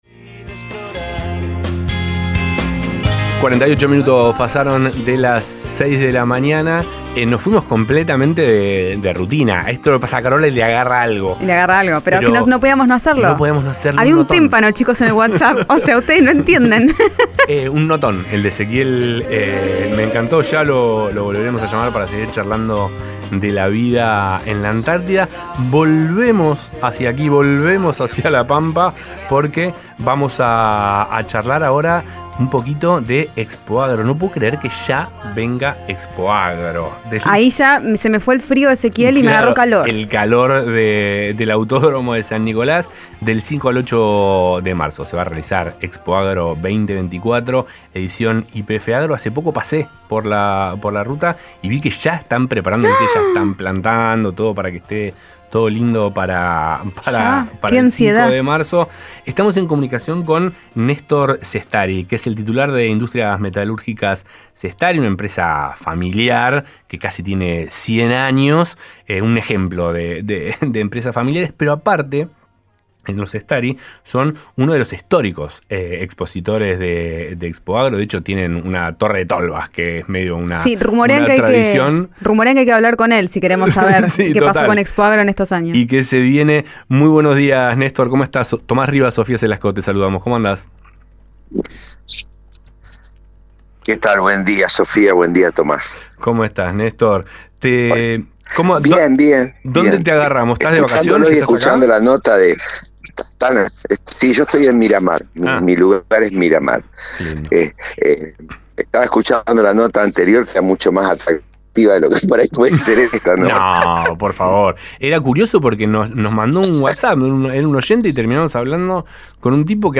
Inicio > Rivadavia Agro > Feria Expoagro 2024: Entrevistamos